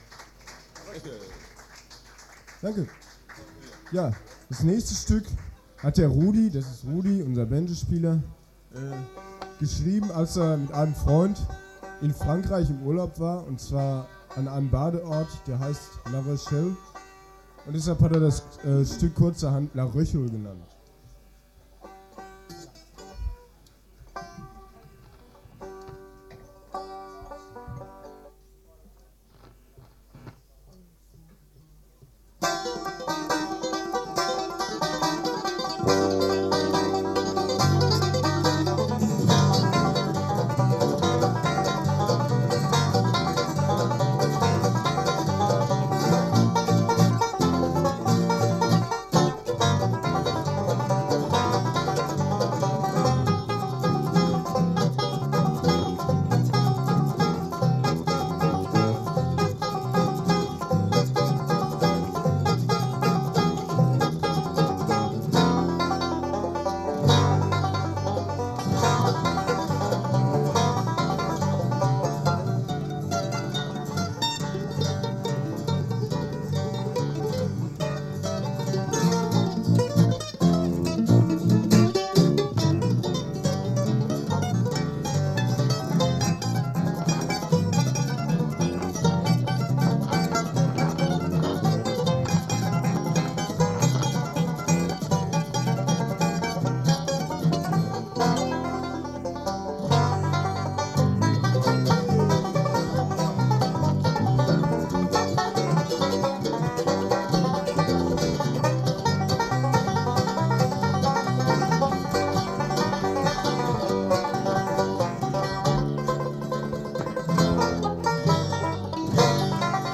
Banjo oder Sehnsucht?